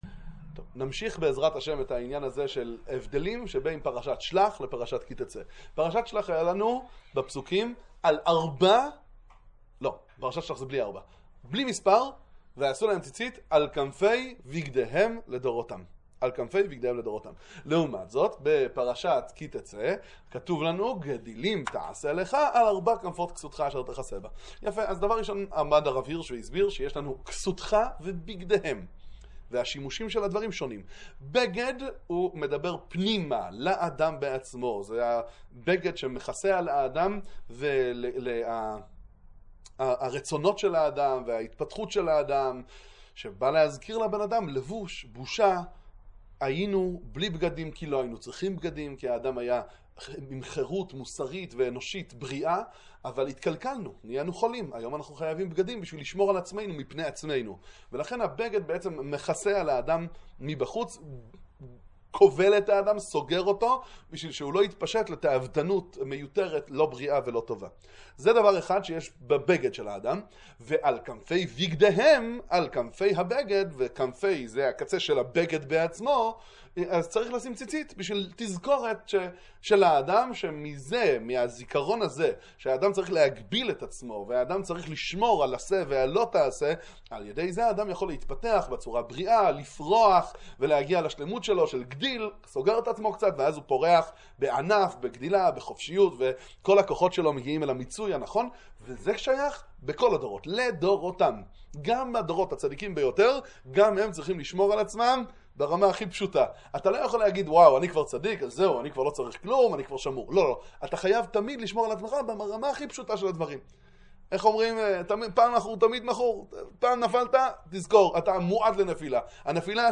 שיעור חמישי. המסר של הציצית לעולם כולו.